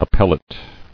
[ap·pel·late]